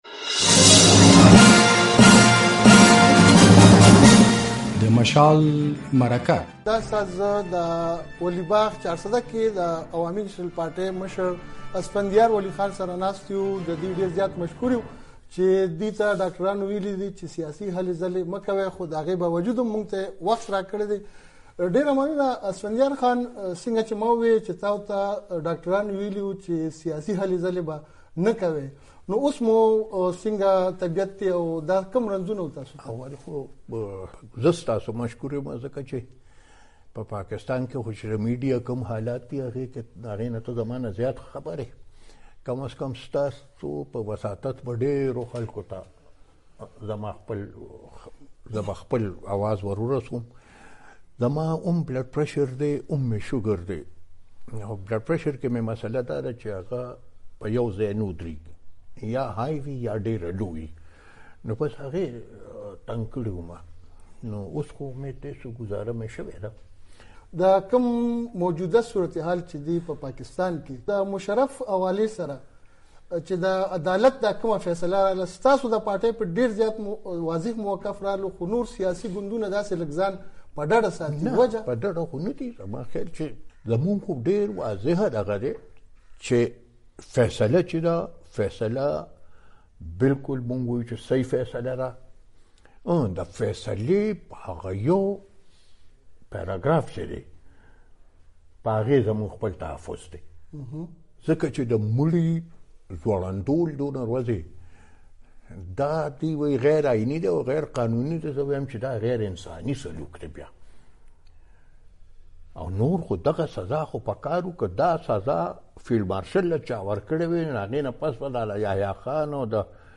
له اسفندیار ولي خان سره د مشال مرکه